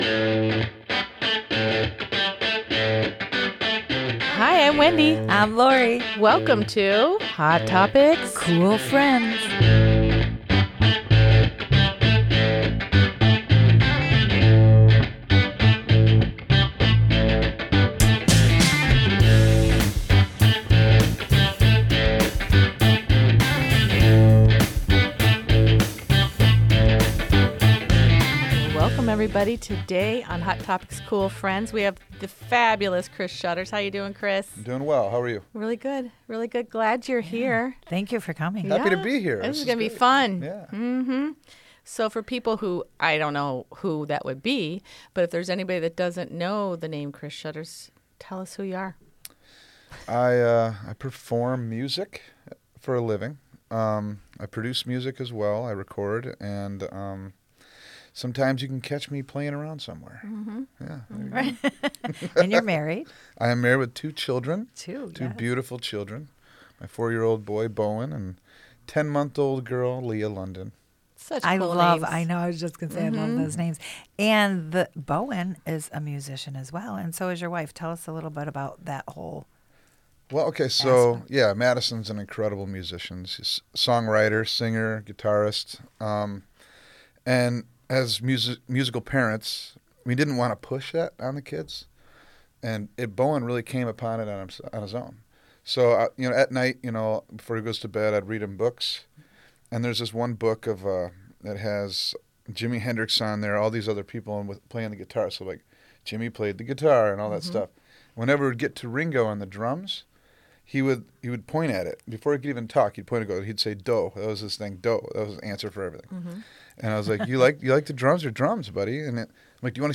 Whether you’re a blues-rock fan or someone chasing creative dreams, this conversation hits all the highs and honest moments of life as an artist.